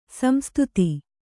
♪ samstuti